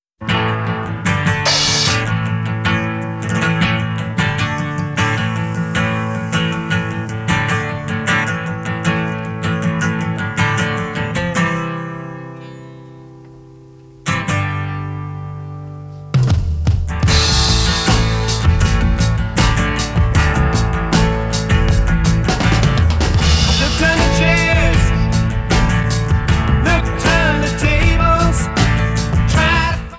The 2006 CDs sound a bit louder.